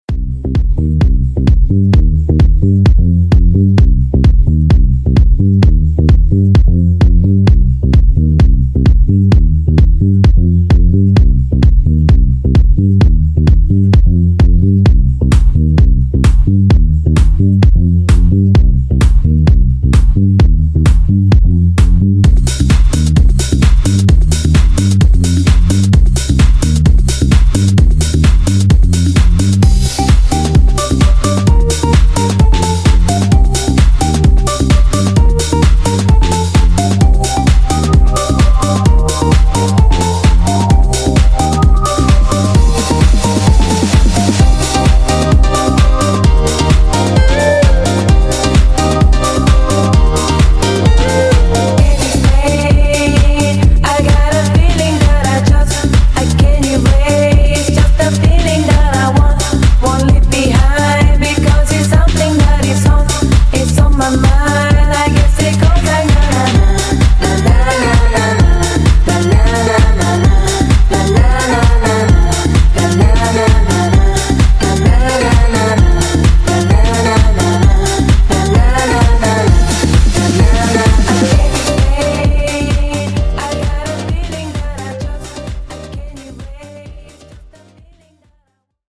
Genre: 90's
Clean BPM: 100 Time